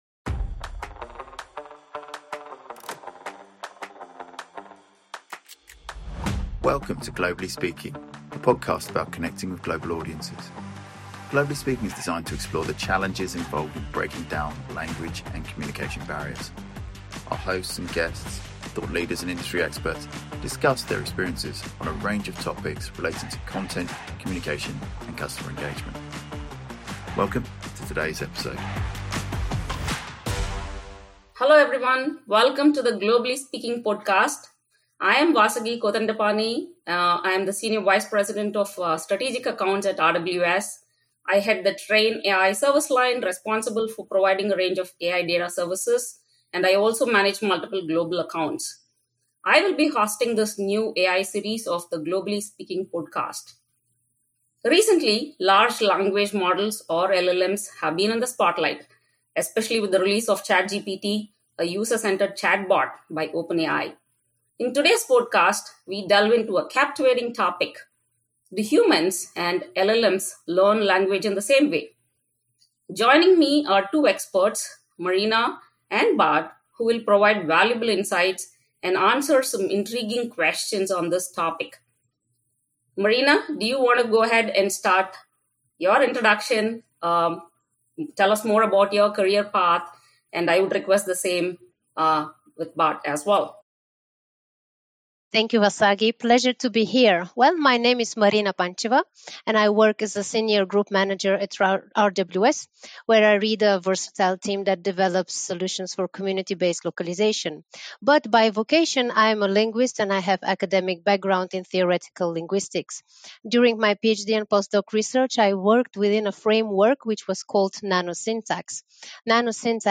discussion